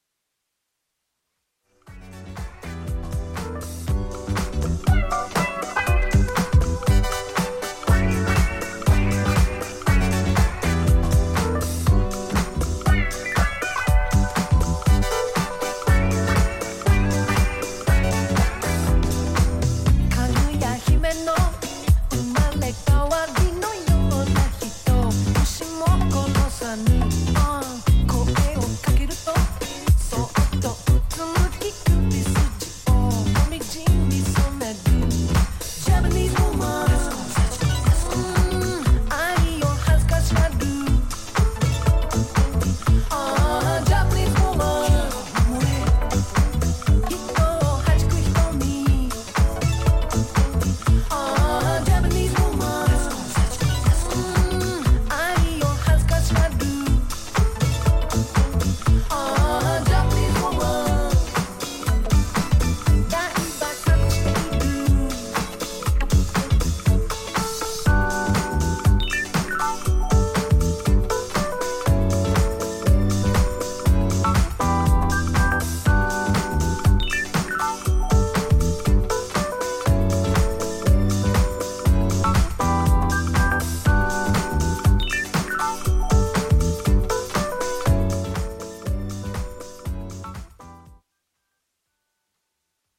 ジャンル(スタイル) NU DISCO / DISCO / RE-EDIT